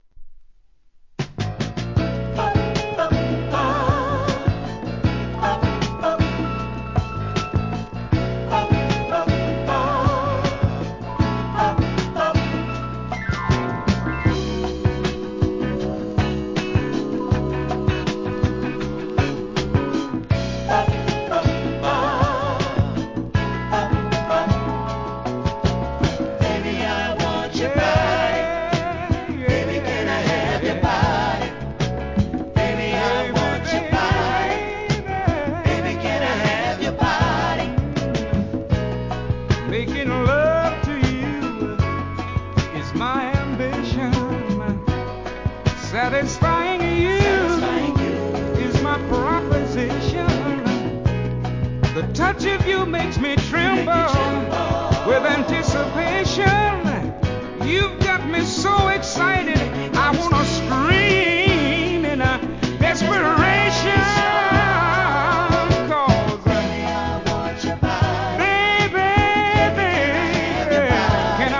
SOUL/FUNK/etc...
メロウ・ミディアム